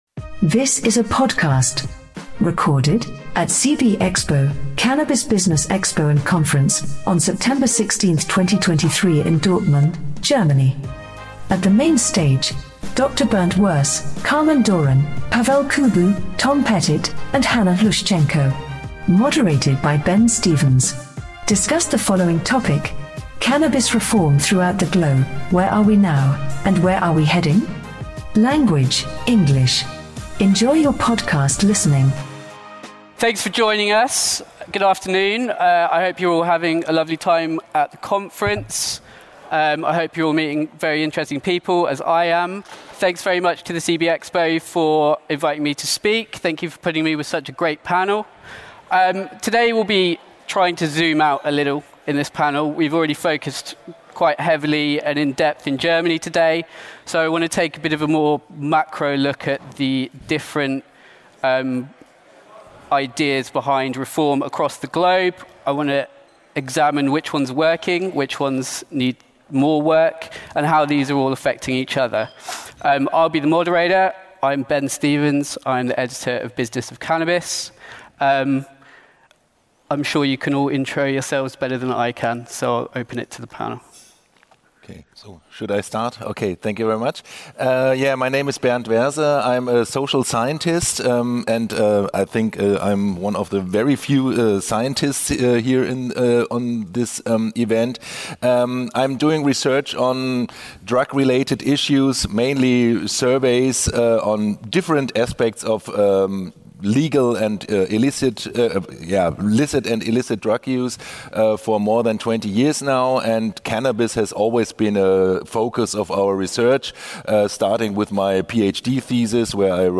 In this panel, we’ll dive into each of these emerging strategies, explore how they are influencing one another, and consider the budding global economic, policy and social trends which are shaping the development of the international cannabis industry.